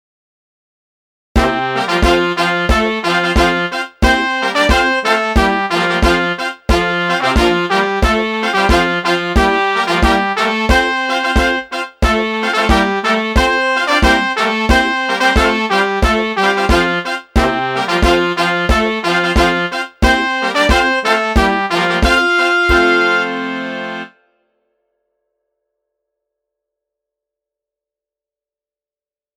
最初はゆっくりとしたノーマルものから、大部分の人ができるようになったときのレベルを上げてテンポを上げた音源も掲載。
90 Kintaro_temp90
Kintaro_temp90.mp3